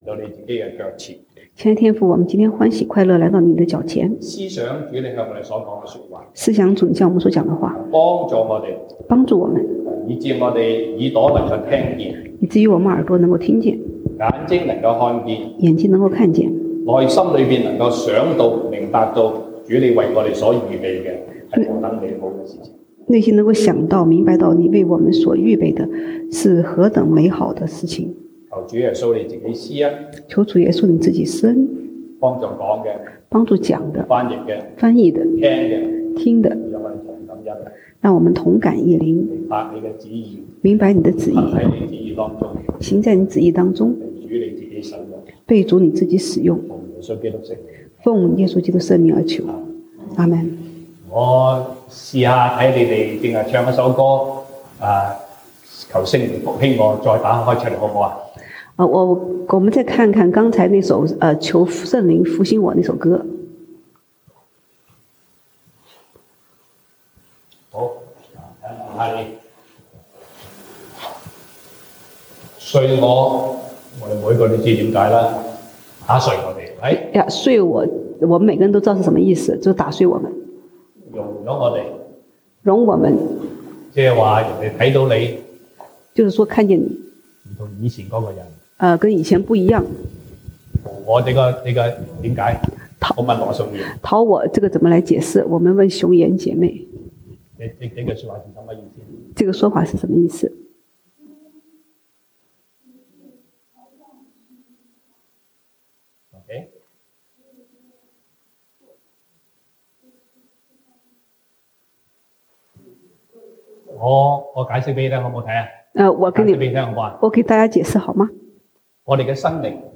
西堂證道(粵語/國語) Sunday Service Chinese: 靠主的力量事奉神乃是恩典
Passage: 歌林多前書 1 Corinthians 3:1-23 Service Type: 西堂證道(粵語/國語) Sunday Service Chinese